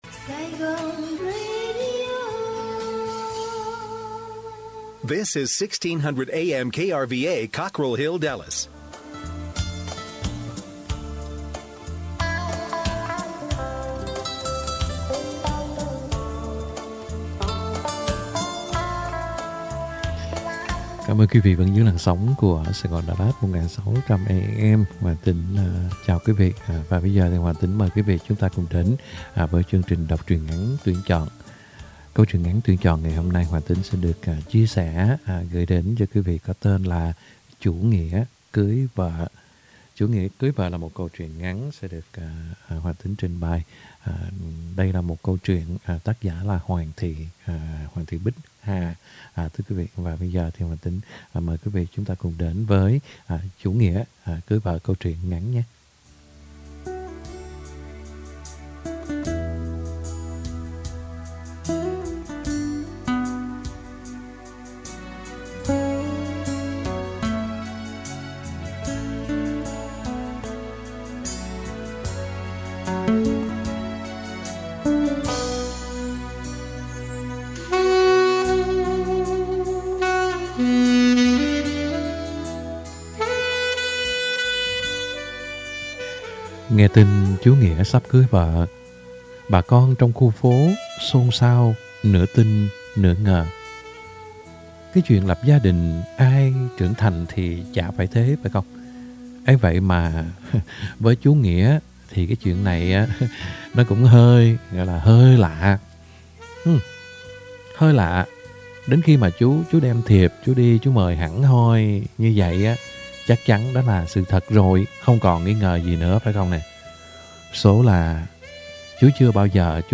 Đọc Truyện Ngắn = Chú Nghĩa Cưới Vợ - 02/15/2022 | Radio Saigon Dallas - KBDT 1160 AM